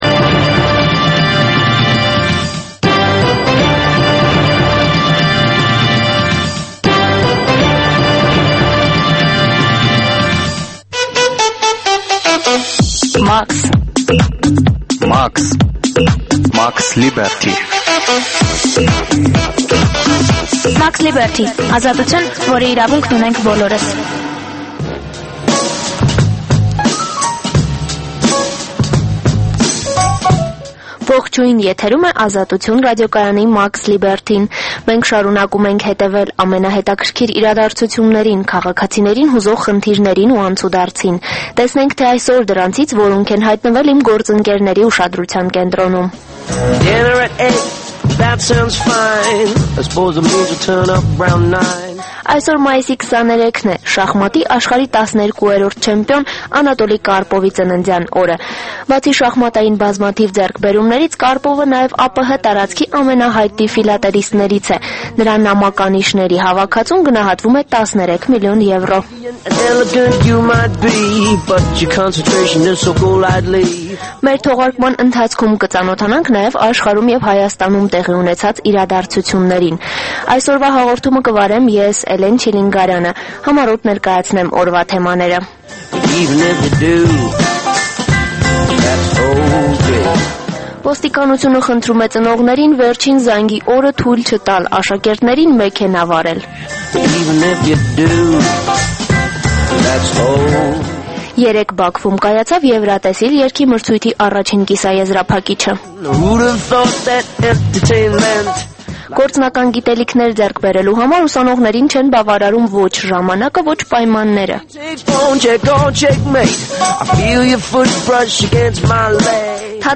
Տեղական եւ միջազգային լուրեր, երիտասարդությանը առնչվող եւ երիտասարդությանը հուզող թեմաներով ռեպորտաժներ, հարցազրույցներ, երիտասարդական պատմություններ, գիտություն, կրթություն, մշակույթ: